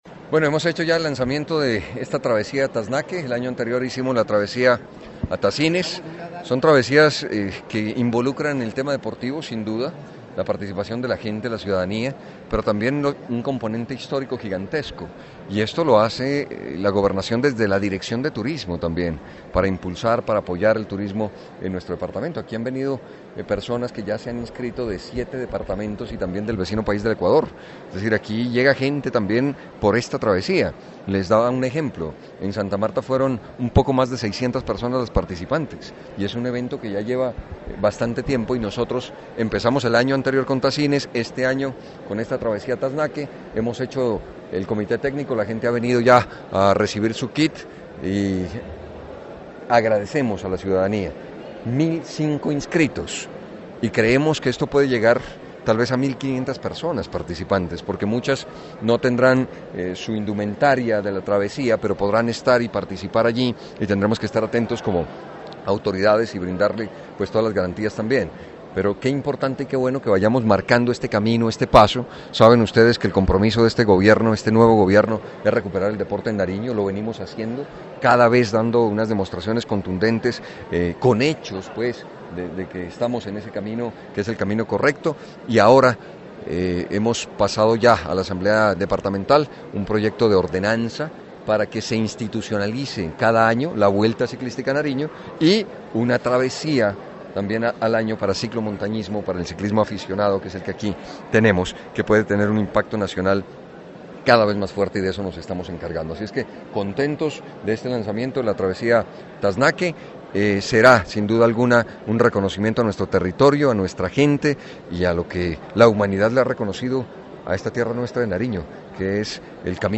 El mandatario de los nariñenses, Camilo Romero, saludó en el parque interno del edificio de la gobernación a los más de mil ciclistas que el domingo recorrerán el tramo entre Pasto y Yacuanquer.
GOBERNADOR_CAMILO_ROMERO_-_LANZAMIENTO_TRAVESA_A_TASNAQUE.mp3